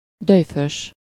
Ääntäminen
GenAm: IPA : /ˈæɹəɡənt/